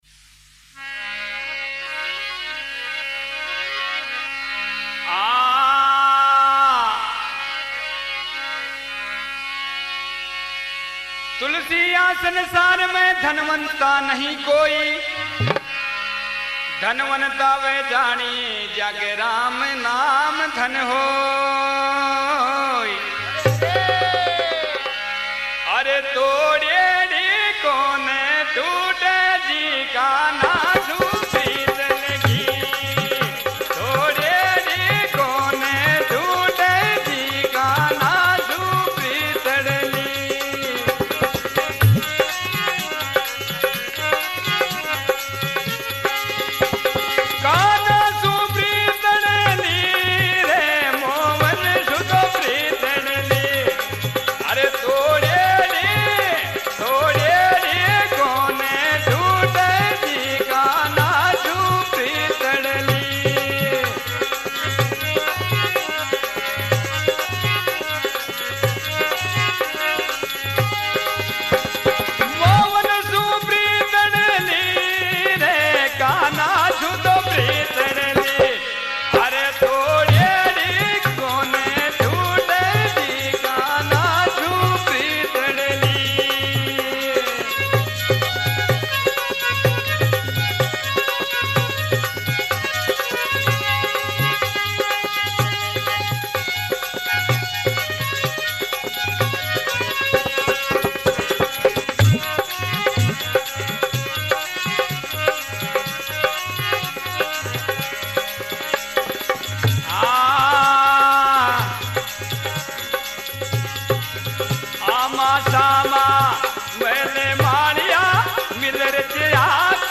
Rajasthani Songs